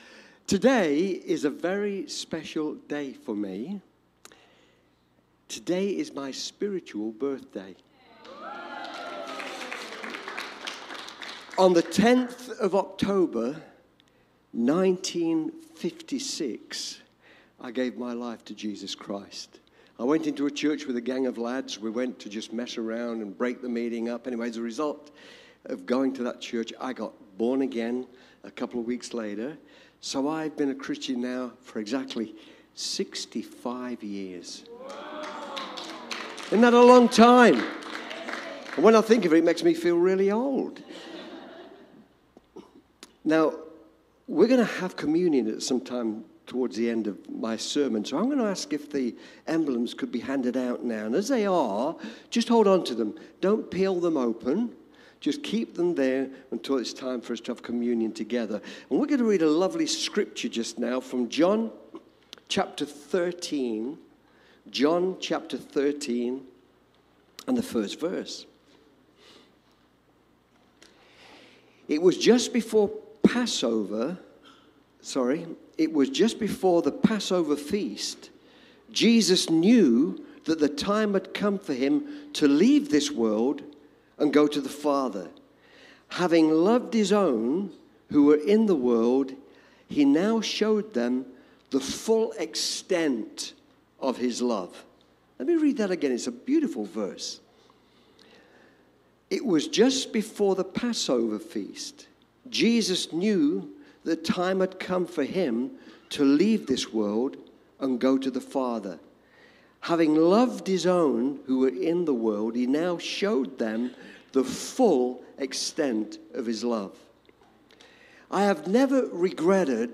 Cityview-Church-Sunday-Service-Loving-us-to-the-End.mp3